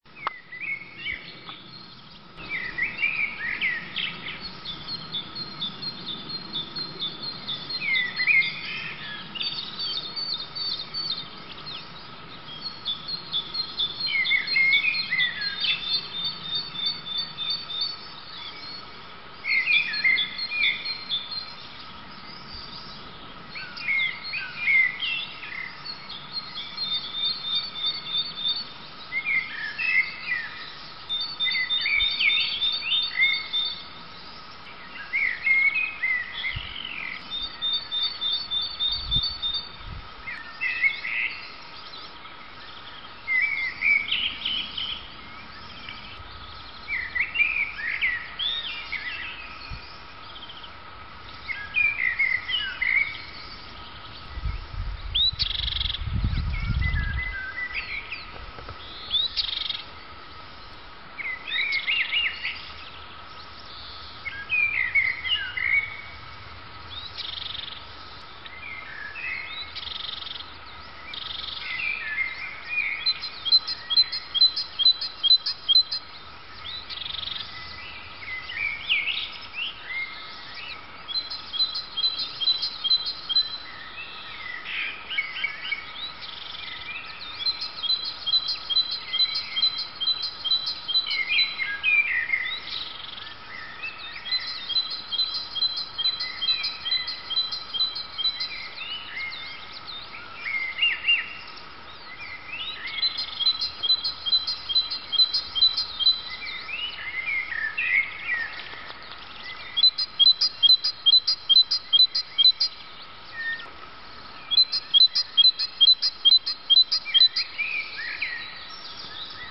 vogel.mp3